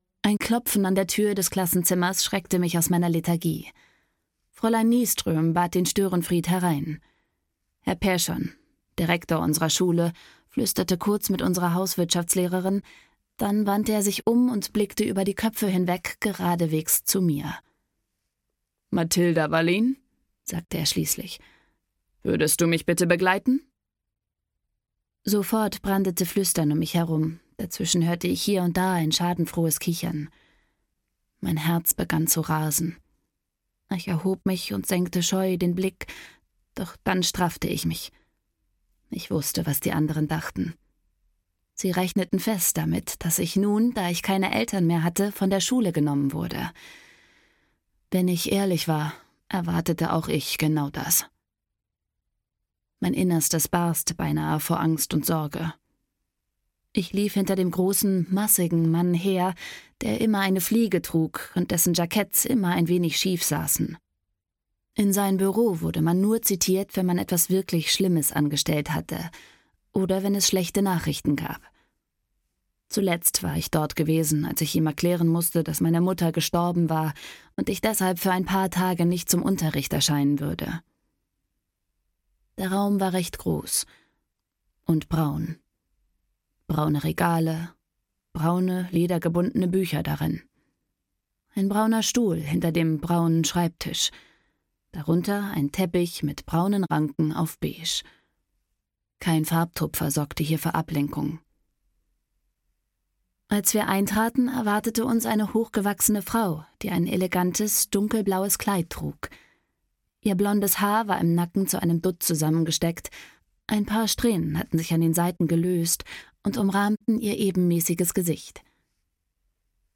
Die Frauen vom Löwenhof – Mathildas Geheimnis (Die Löwenhof-Saga 2) - Corina Bomann - Hörbuch